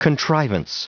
Prononciation du mot contrivance en anglais (fichier audio)
Prononciation du mot : contrivance